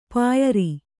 ♪ pāyari